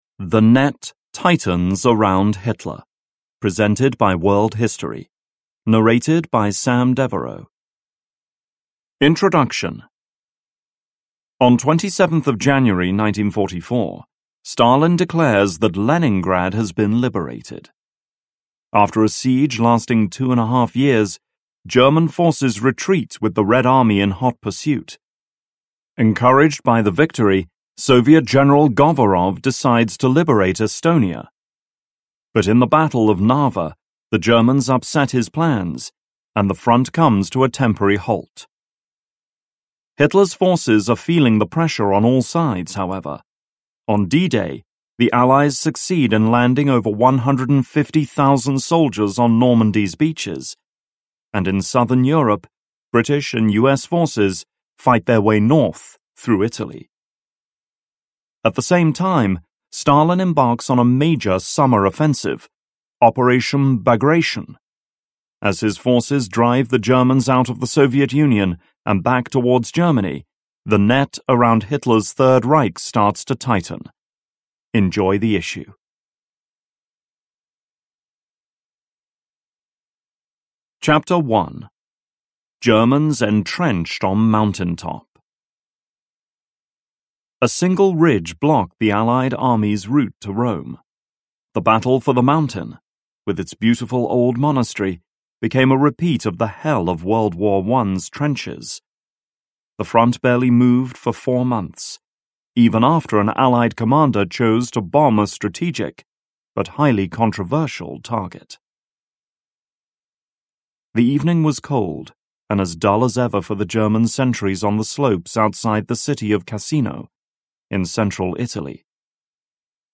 The Net Tightens Around Hitler (ljudbok) av World History